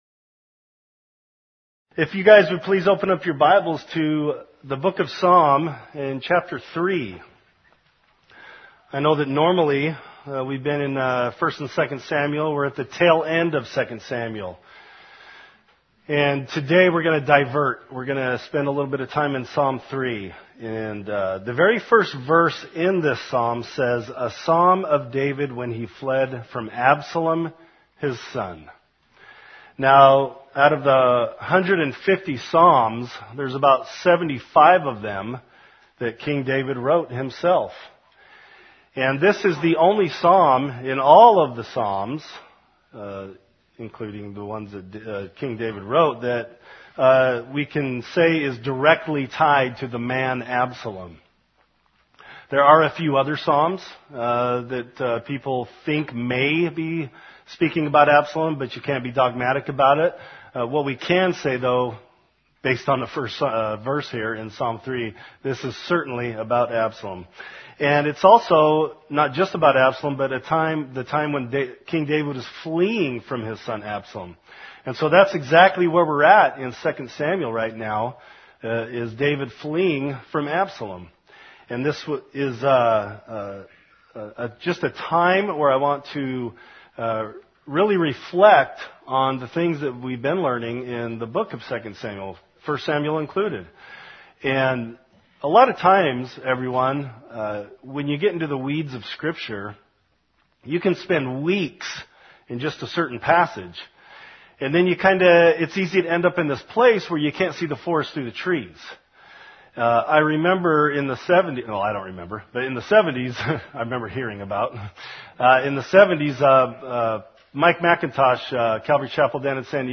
In this topical, companion message to our current study in 2 Samuel